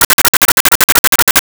Arcade Movement 13.wav